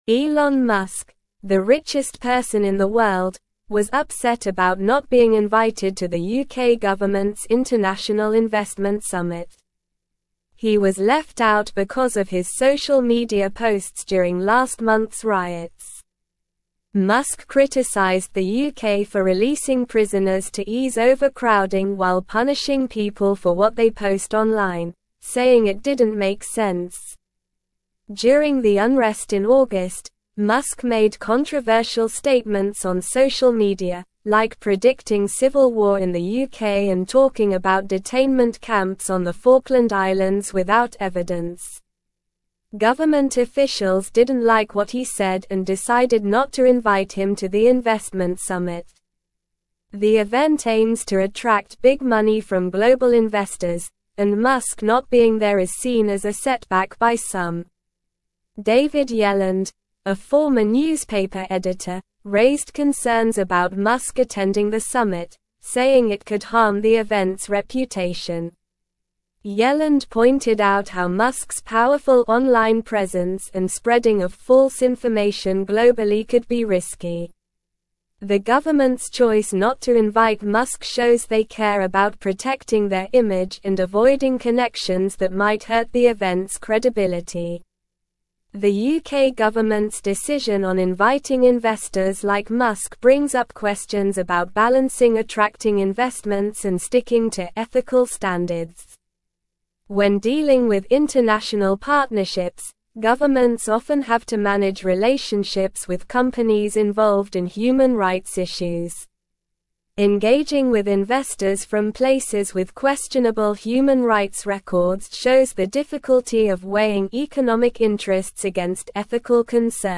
Slow
English-Newsroom-Upper-Intermediate-SLOW-Reading-Elon-Musk-Excluded-from-UK-Investment-Summit-Over-Posts.mp3